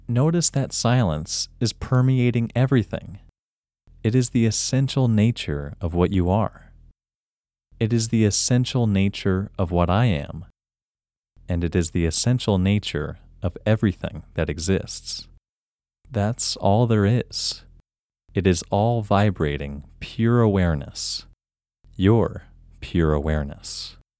WHOLENESS English Male 19
WHOLENESS-English-Male-19.mp3